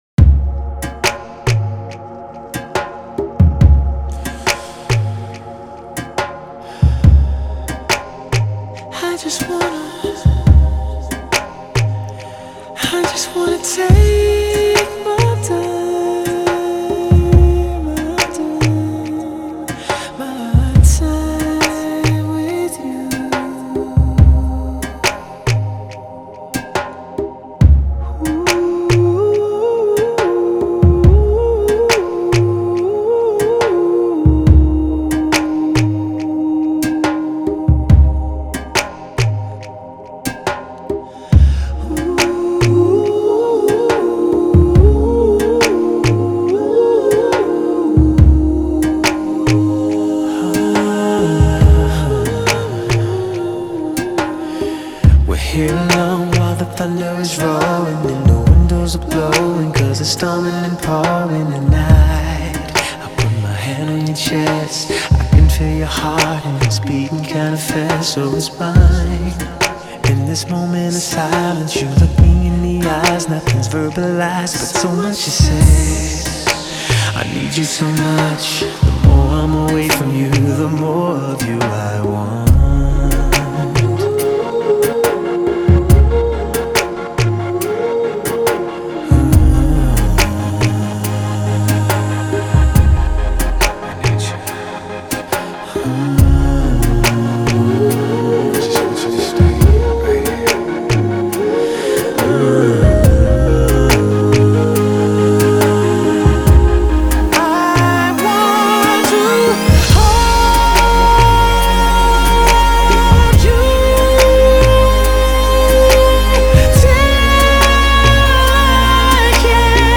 ethereal and jazzy